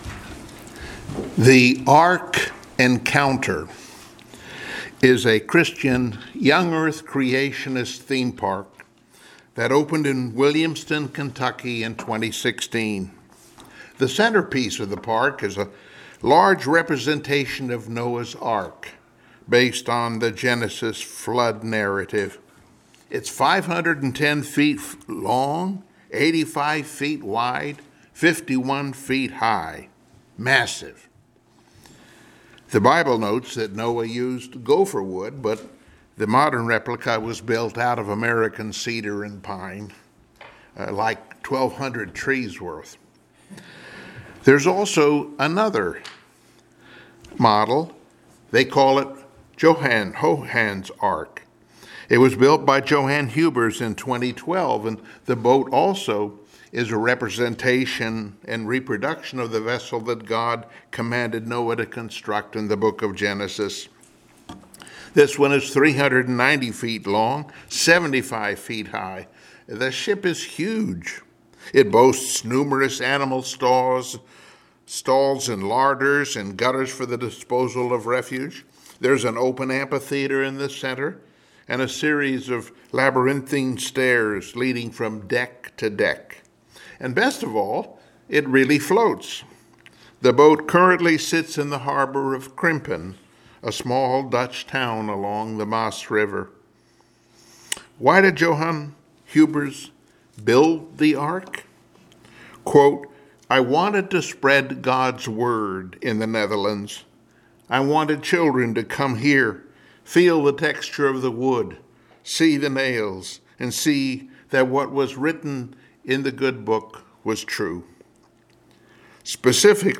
Passage: Mathew 24:36-42 Service Type: Sunday Morning Worship Topics